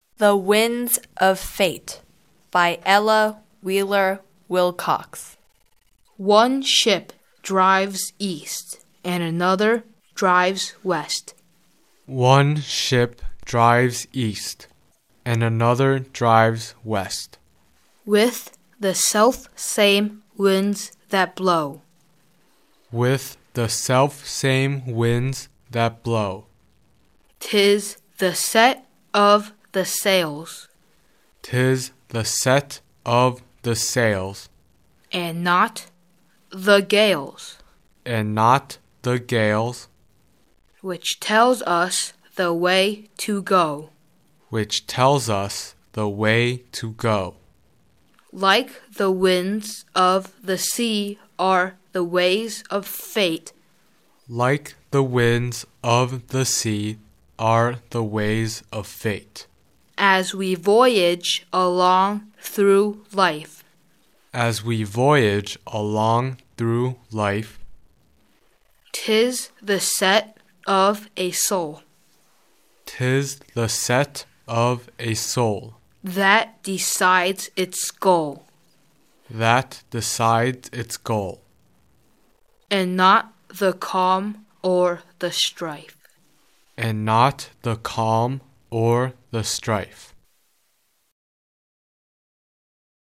English beautiful reciting poems http